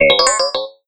parent-tool-sound-error.wav